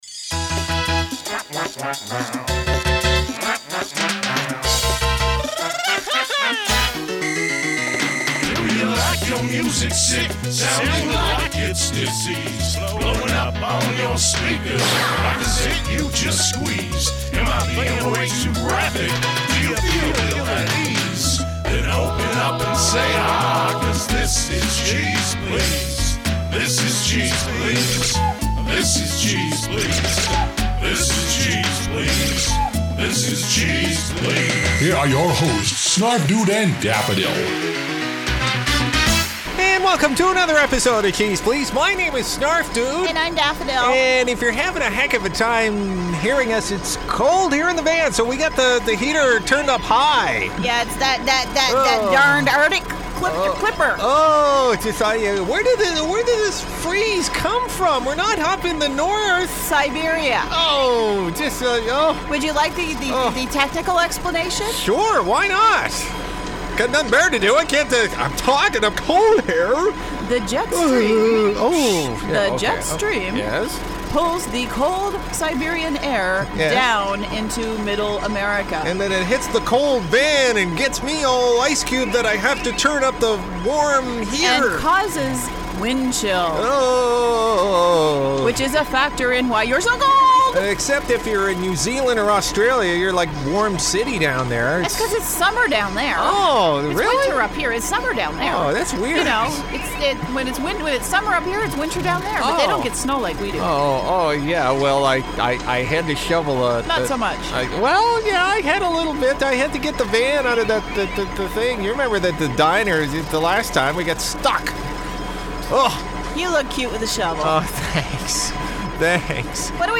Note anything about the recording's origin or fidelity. Our hosts try not to freeze in the van this week (they need blankets!!)....